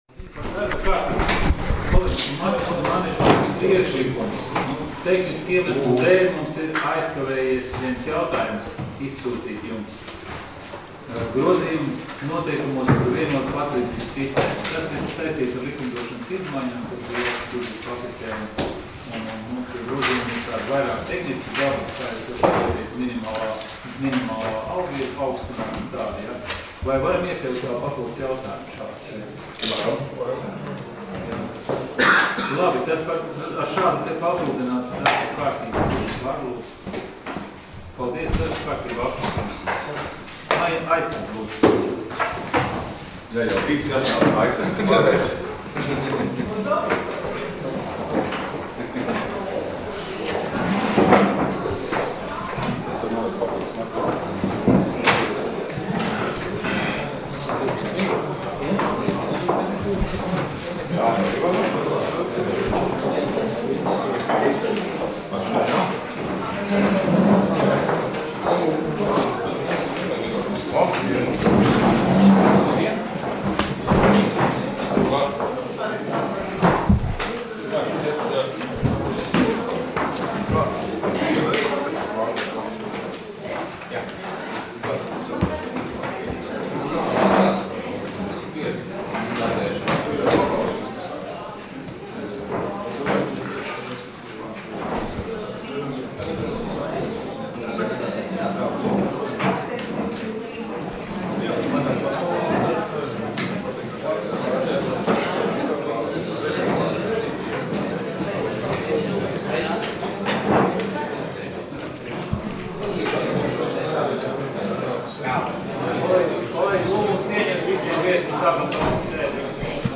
Domes sēdes 20.01.2017. audioieraksts